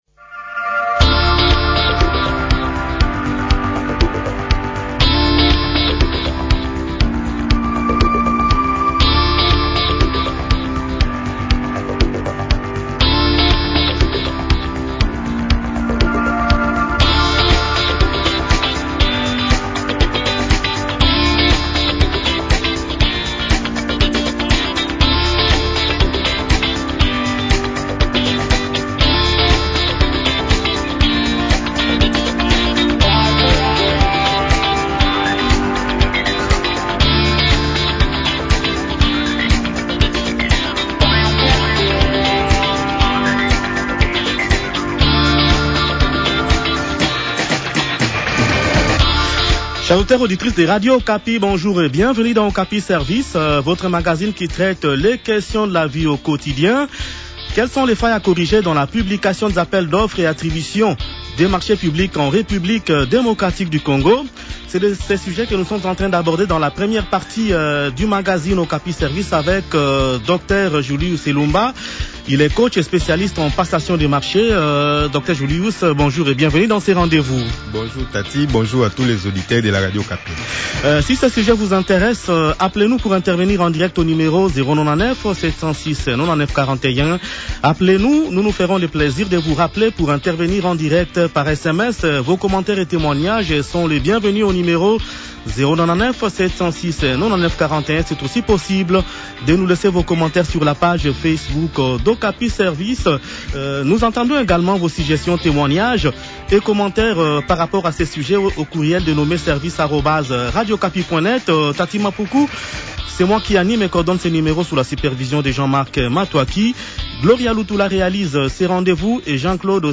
coach et expert en passation des marchés publics.